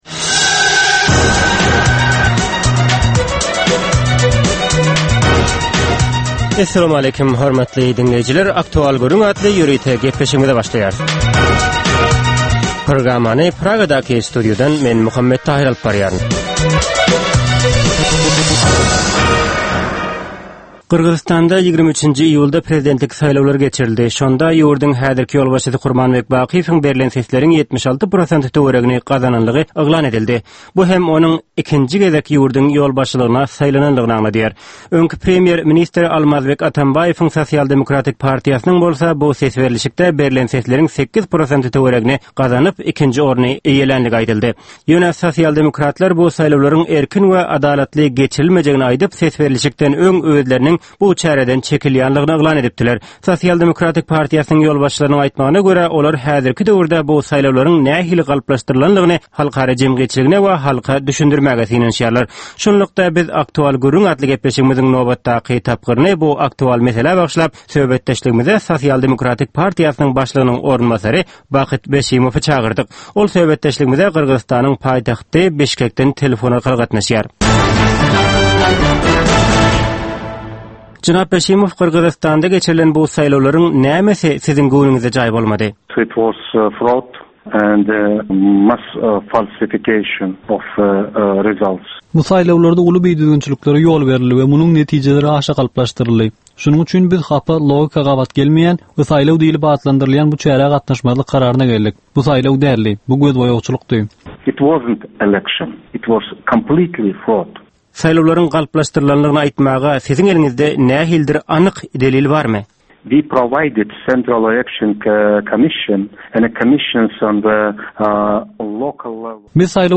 Hepdänin dowamynda Türkmenistanda ýa-da halkara arenasynda ýüze çykan, bolup geçen möhüm wakalar, meseleler barada anyk bir bilermen ýa-da synçy bilen geçirilýän 10 minutlyk ýörite söhbetdeslik. Bu söhbetdeslikde anyk bir waka ýa-da mesele barada synçy ýa-da bilermen bilen aktual gürründeslik geçirilýär we meselänin dürli ugurlary barada pikir alsylýar.